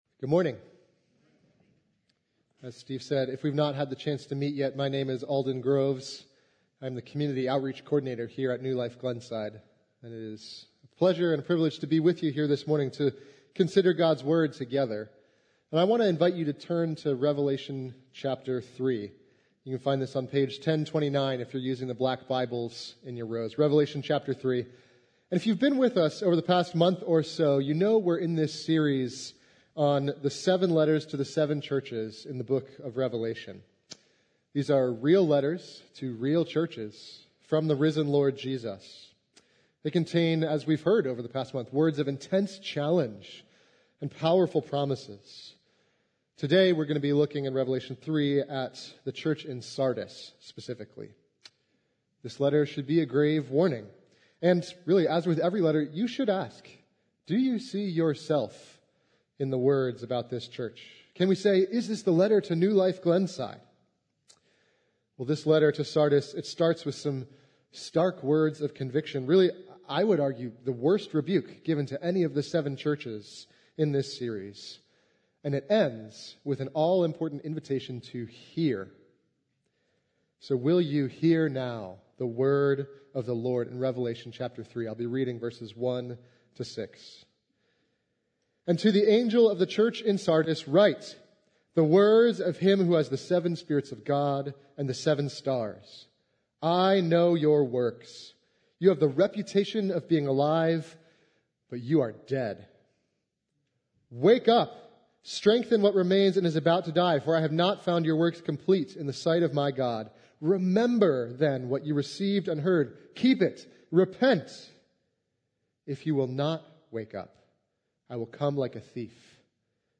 A message from the series "Dear Church ."